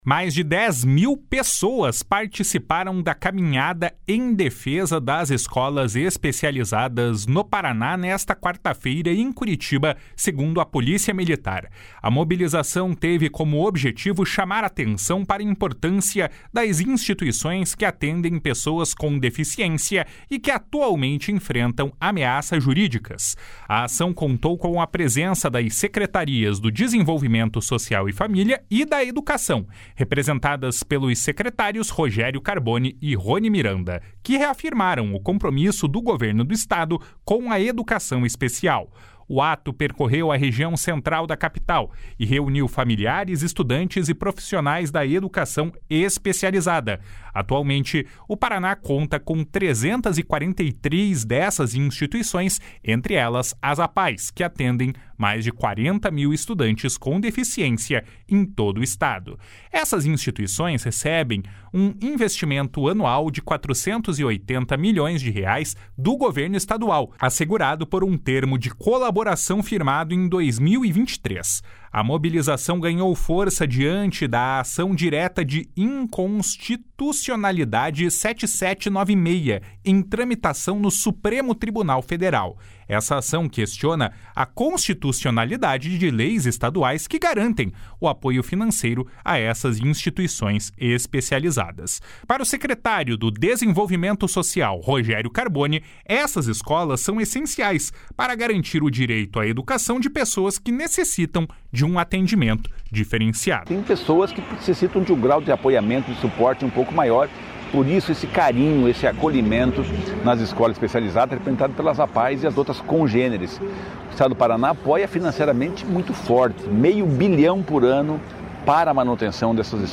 Para o secretário do Desenvolvimento Social, Rogério Carboni, as escolas especializadas são essenciais para garantir o direito à educação de pessoas que necessitam de atendimento diferenciado. // SONORA ROGÉRIO CARBONI //